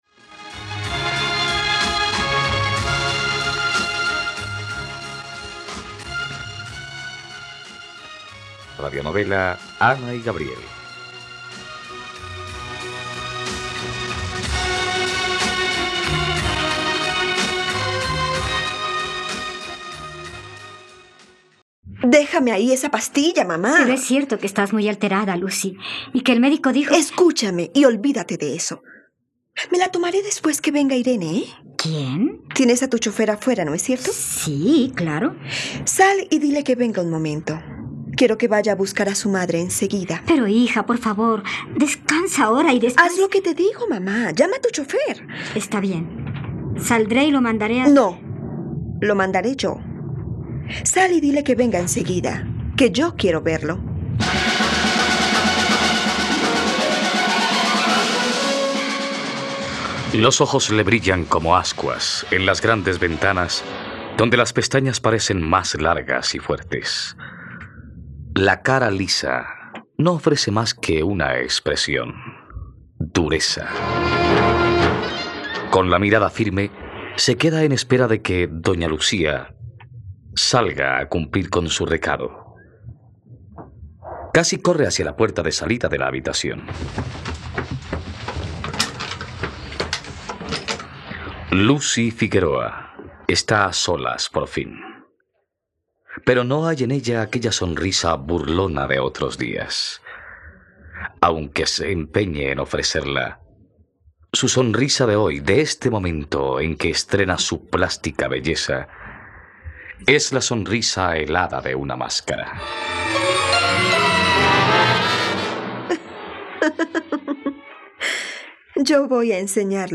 Ana y Gabriel - Radionovela, capítulo 59 | RTVCPlay